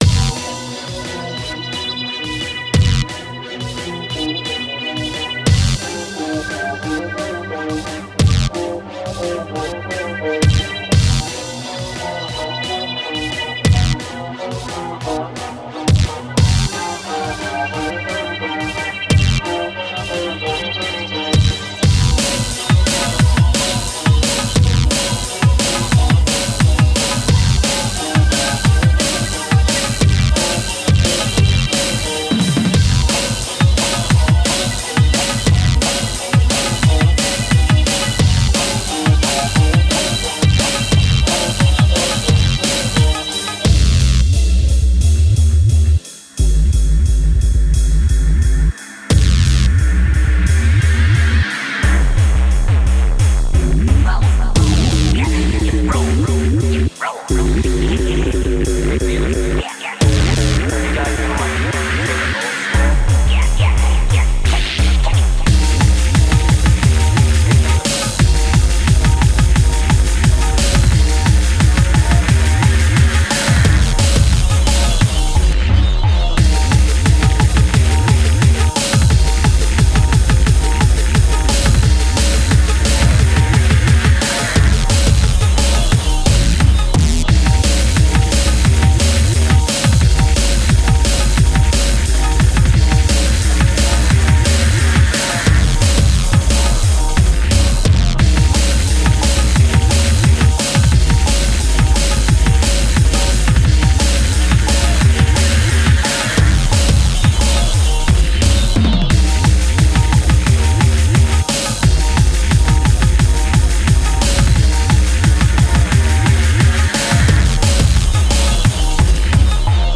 urban, dark and corrosive